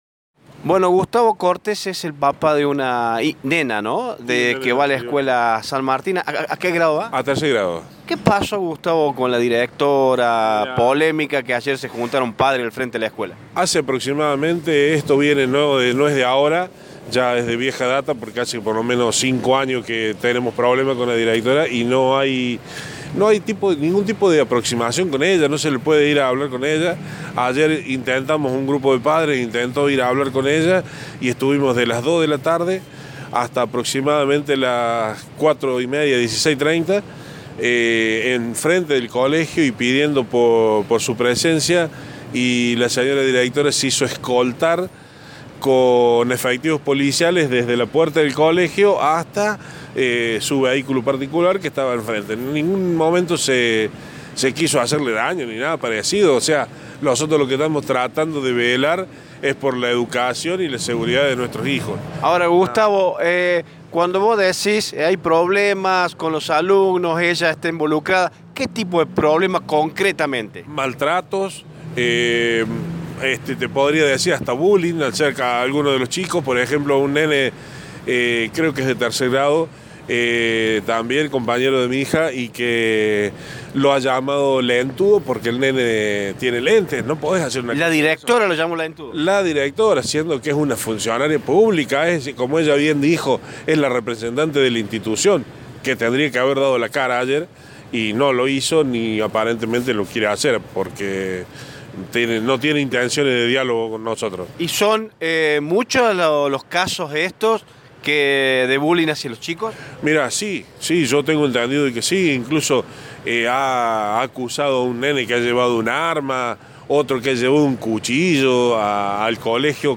Este era el testimonio de una de las madres denunciantes: